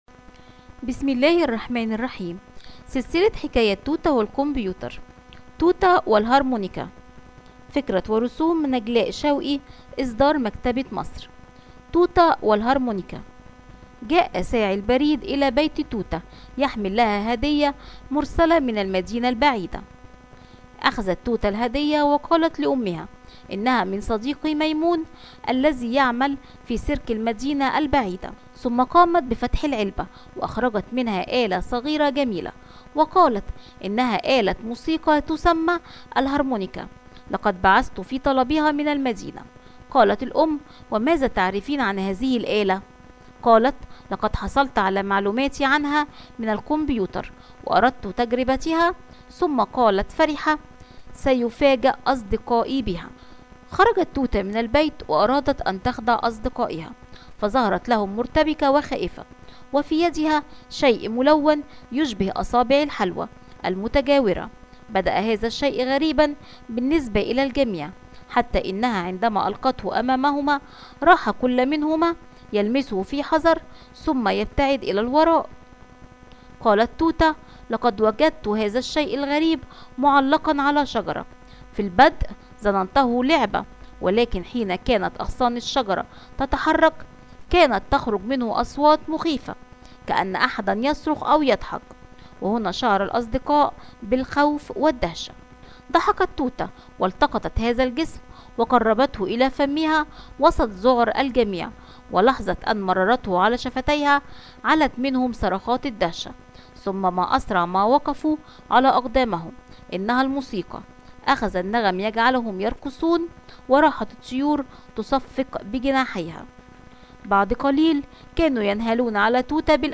Tute ve Mızıka- Arapça Sesli Hikayeler